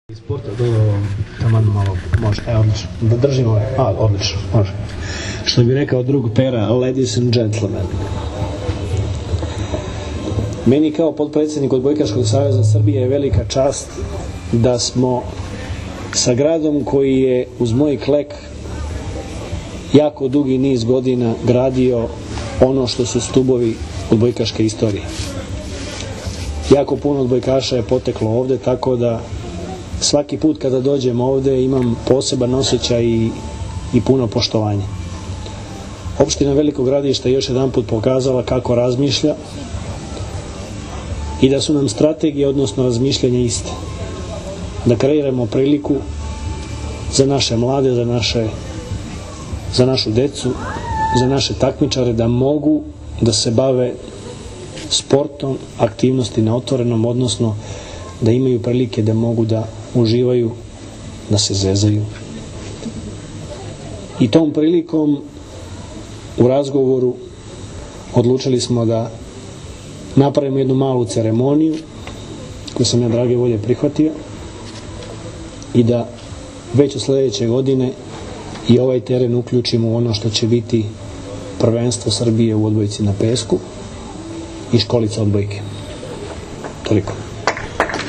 Na Srebrnom jezeru je danas svečano otvoren novi teren za beach – volley.
IZJAVA VLADIMIRA GRBIĆA